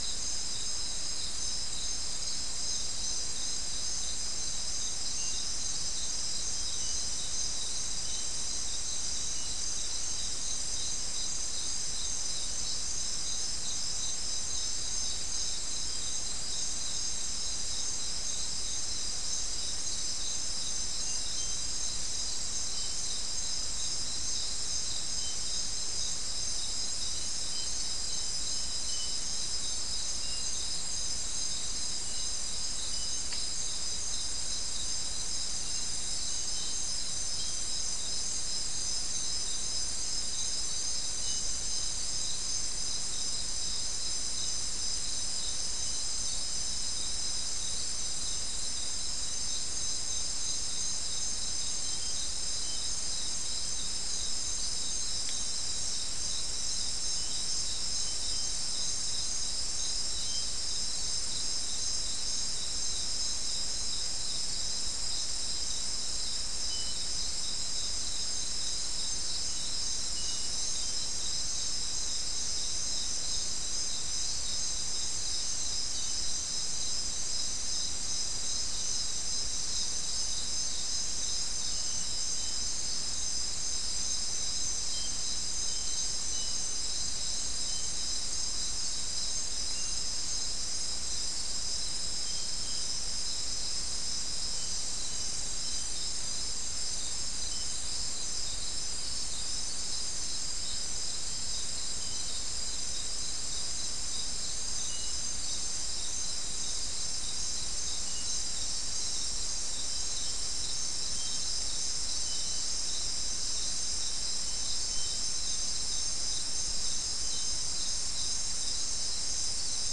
Soundscape Recording
South America: Guyana: Mill Site: 2
Recorder: SM3